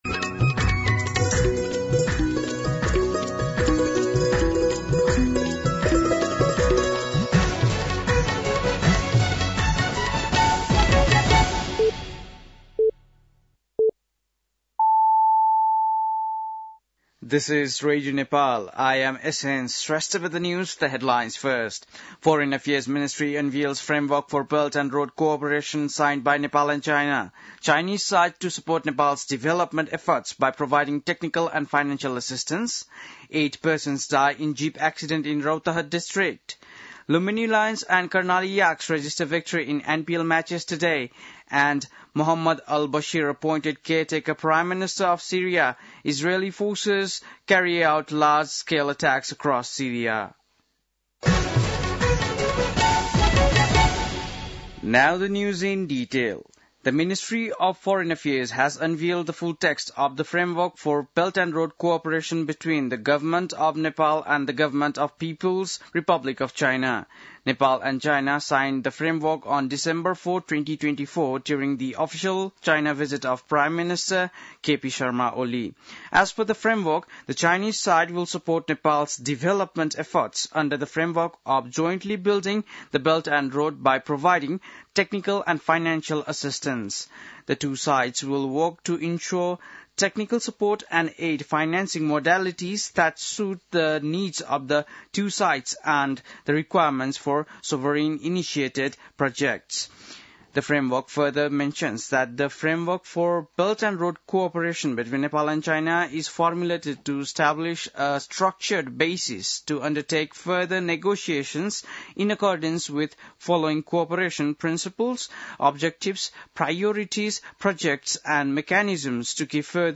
बेलुकी ८ बजेको अङ्ग्रेजी समाचार : २६ मंसिर , २०८१
8-PM-English-News-8-25.mp3